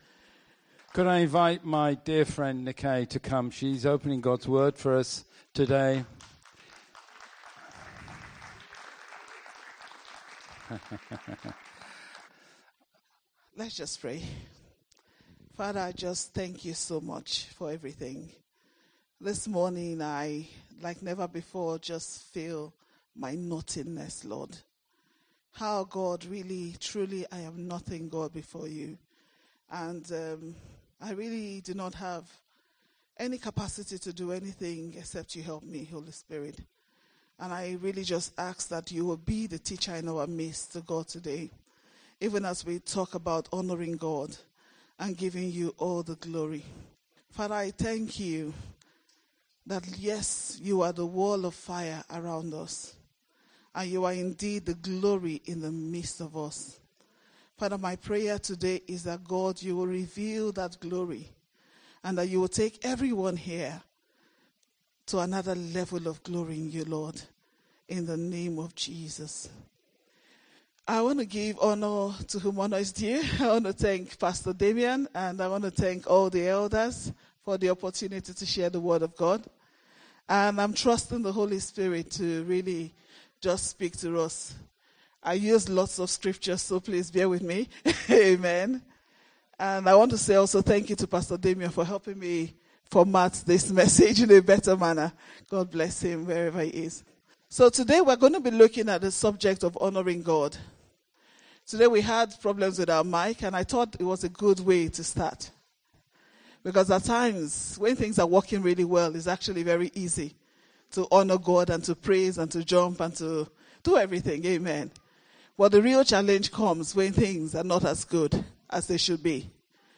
Oct 27, 2019 Honouring God MP3 SUBSCRIBE on iTunes(Podcast) Notes In the Old Testament, the priesthood were given exacting instructions on how to honour the sacrifices required by God. In this challenging sermon